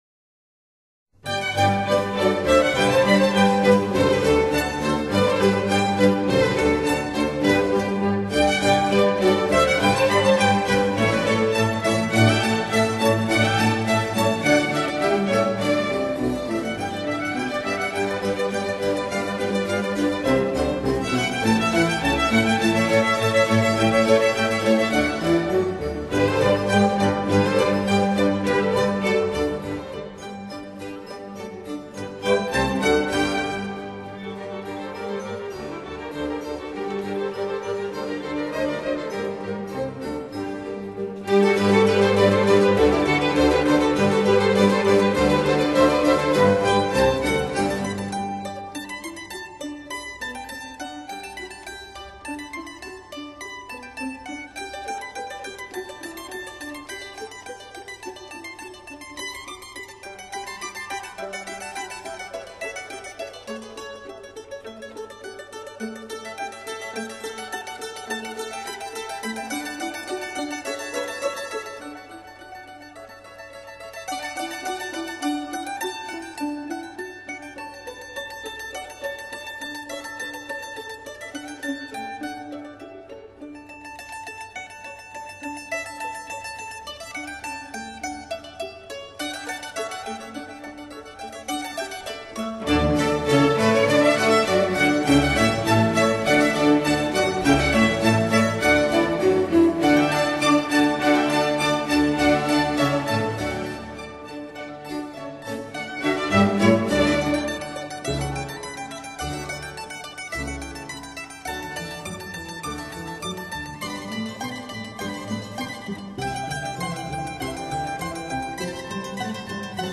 Allegro}    [0:04:10.35]